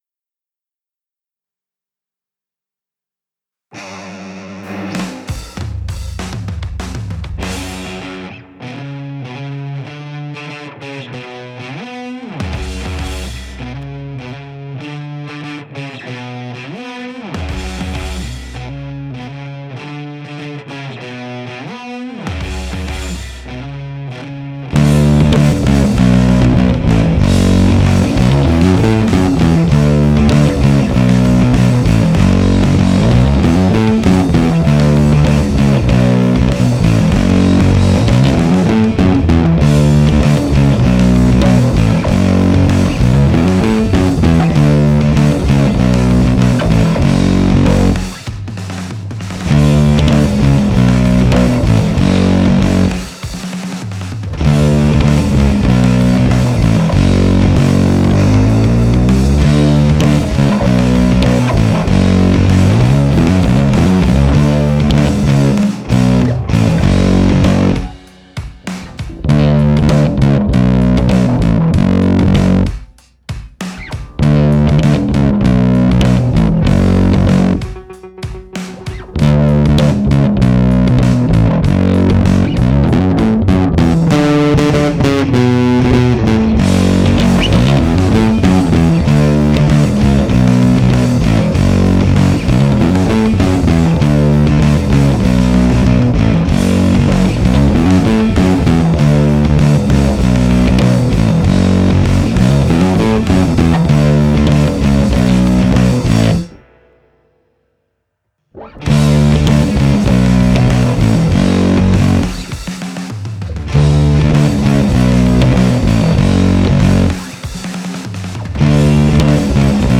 (instrumental)
Download mp4 Download mp3 Cover / Bass only Tablature